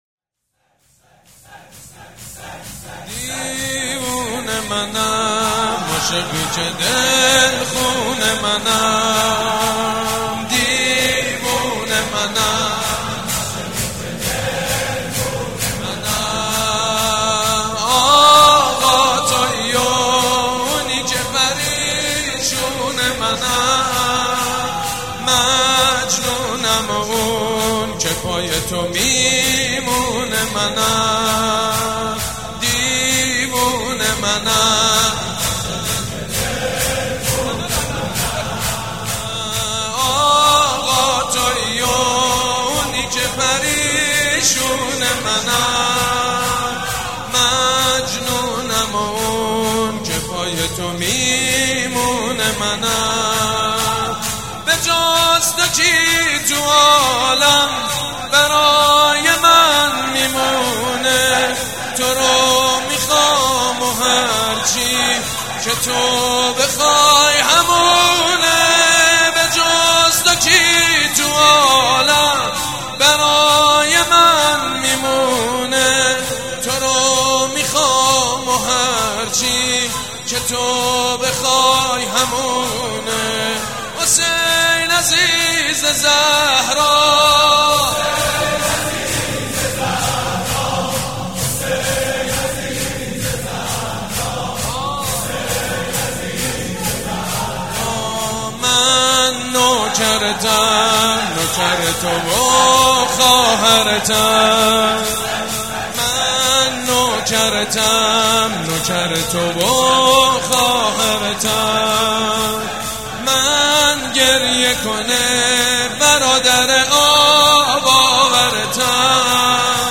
شور
مداح
حاج سید مجید بنی فاطمه
شهادت امام جواد (ع)
شور دوم_دیوونه منم.mp3